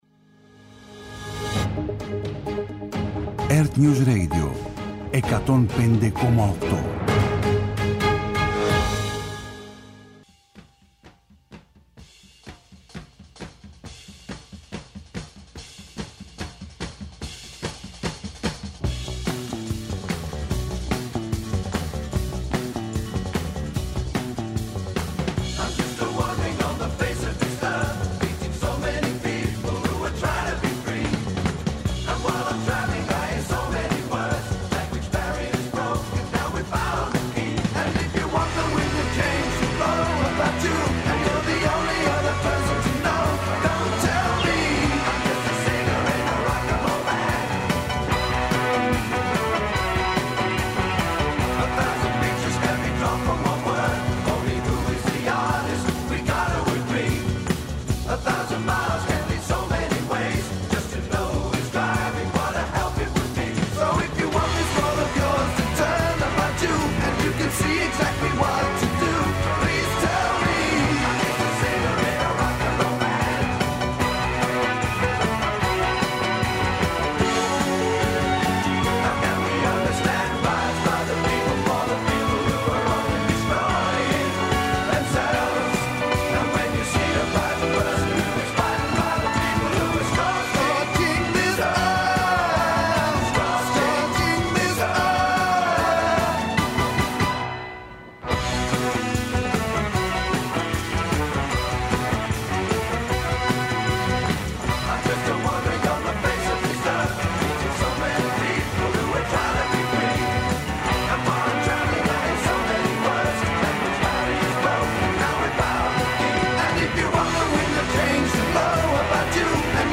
Progressive